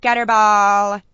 gutterball_6.wav